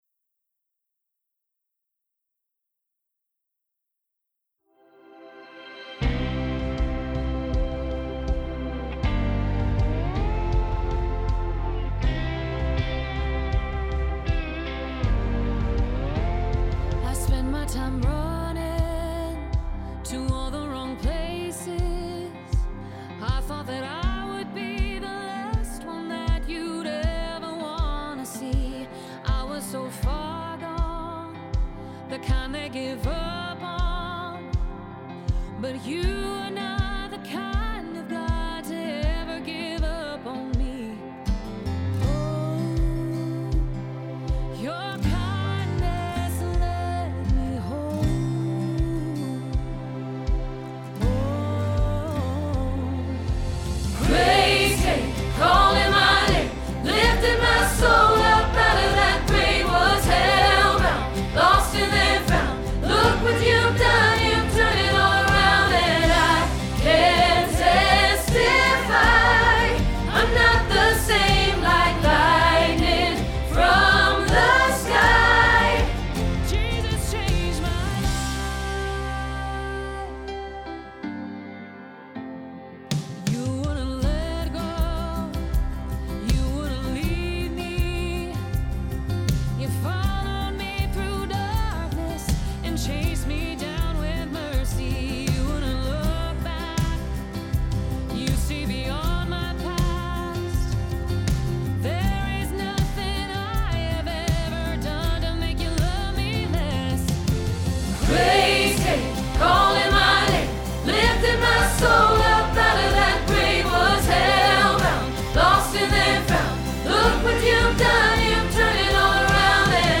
Jesus Changed My Life – Soprano – Hilltop Choir
01-Jesus-Changed-My-Life-Soprano-PTX.mp3